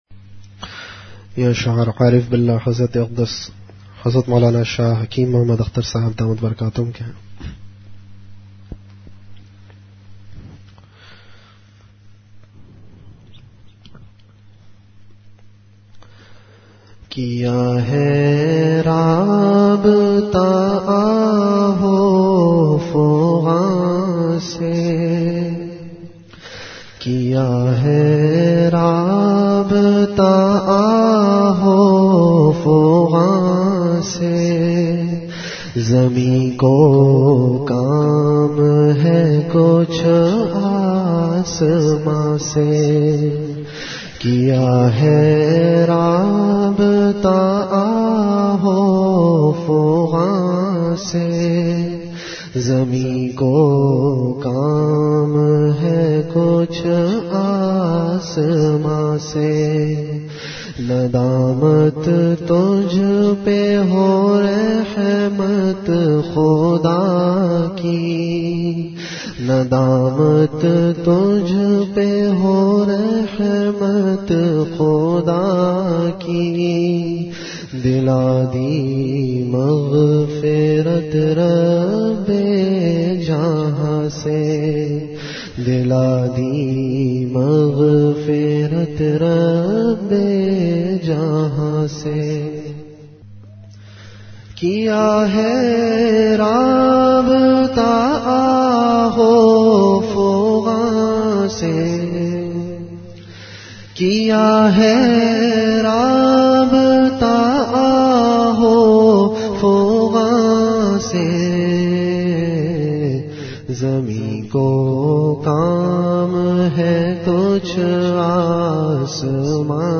Delivered at Home.
Category Ashaar
Event / Time After Isha Prayer